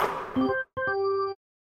Make A Basket Sound Effect
make-a-basket.mp3